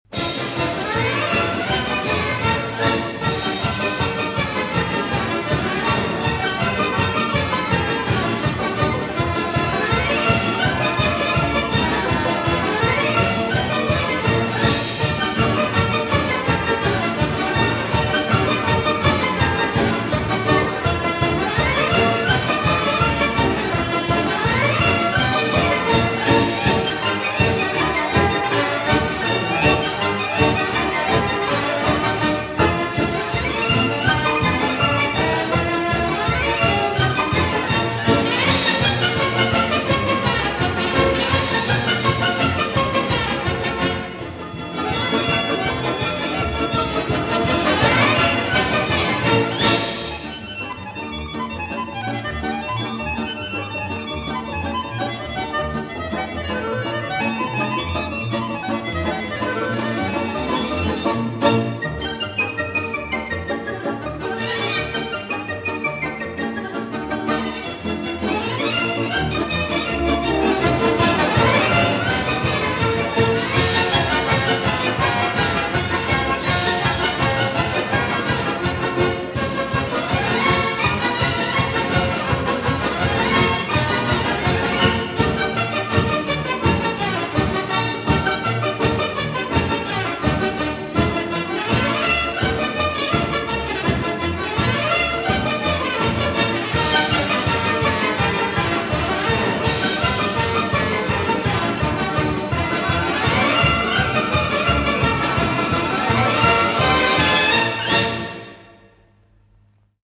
Изображение Беларускі народны танец
Гэты танец, з такімі рухамі як полька, притоп, галоп, з выкарыстаннем розных варыяцый, вельмі вяселы, яркі і эмацыйны.